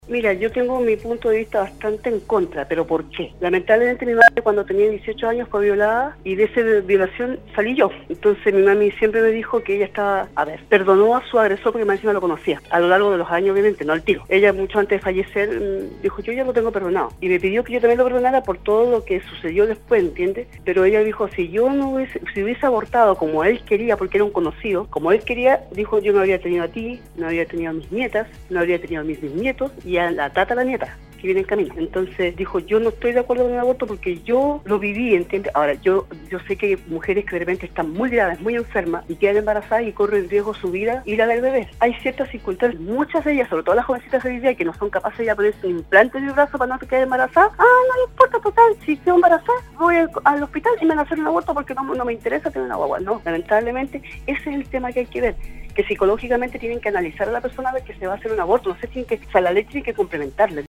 En relación a algunas manifestaciones que se han registrado en algunos países del mundo sobre una nueva campaña en favor del Aborto, algunos auditores expresaron sus inquietudes y opiniones con respecto a este tema que continua siendo controversial y generando polémica en la sociedad.